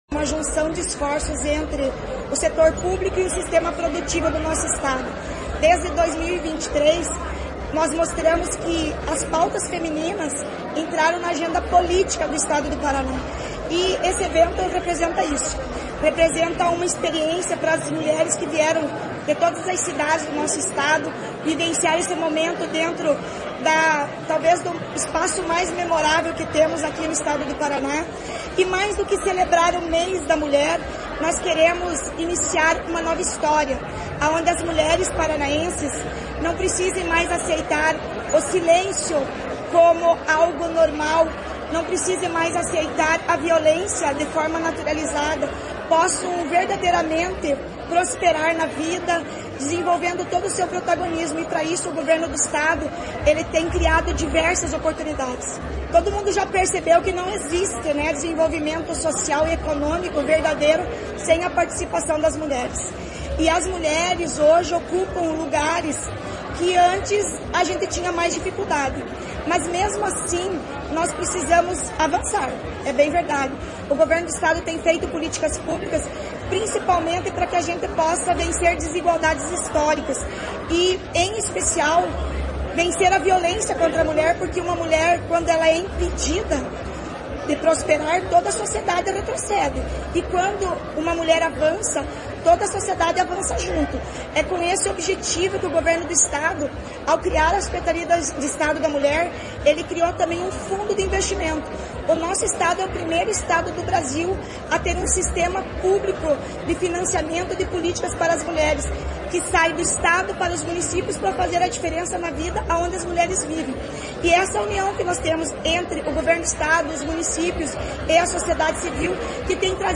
Sonora da secretária da Mulher, Igualdade racial e pessoa idosa, Leandre Dal Ponte, sobre o protagonismo feminino